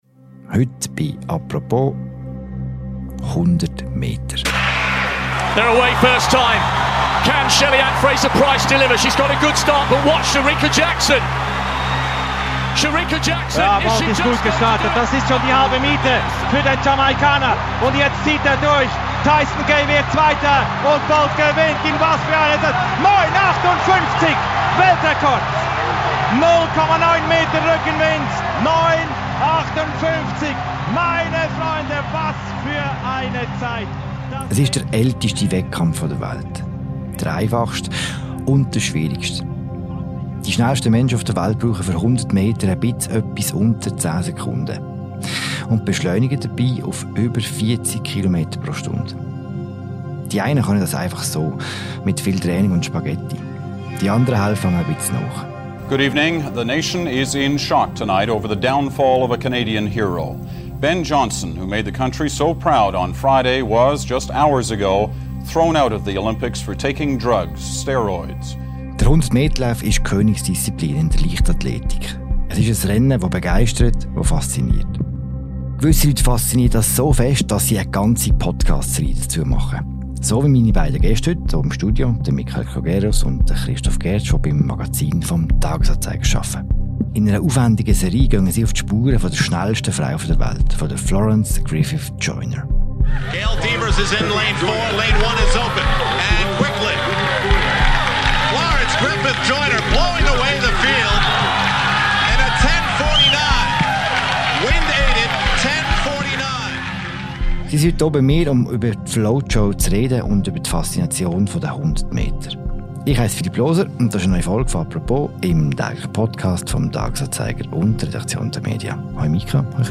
Zum Start der Serie – und im Vorfeld der Olympischen Spiele in Paris – sprechen wir die beiden im Podcast «Apropos» über die Faszination 100-Meter-Lauf und über eine Athletin, die die Sportwelt veränderte – und zu Unrecht vergessen ging.